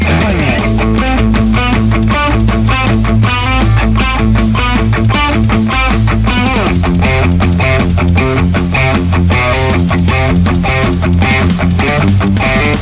It's incredible sounding when pushed! Bright, edgy and alot of attack!
Metal Riff Mix
RAW AUDIO CLIPS ONLY, NO POST-PROCESSING EFFECTS